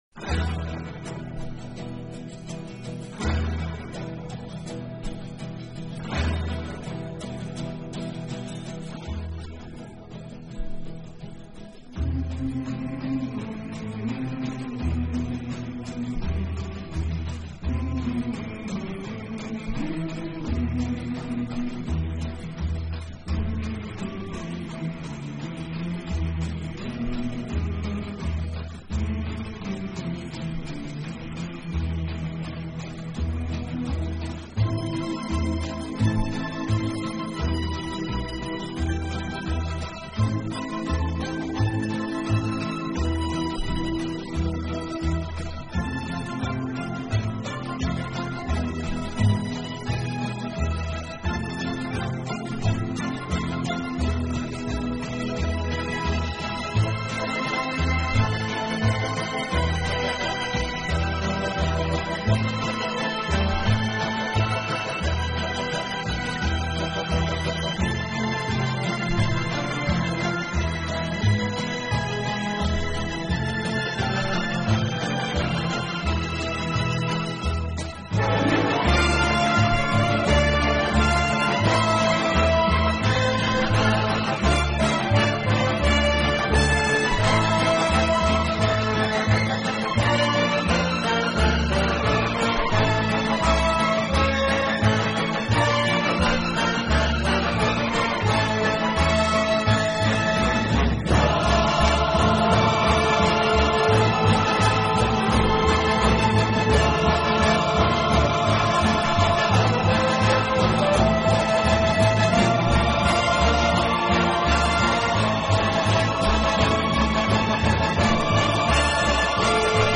好处的管乐组合，给人以美不胜收之感。